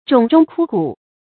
冢中枯骨 zhǒng zhōng kū gǔ 成语解释 冢：坟墓。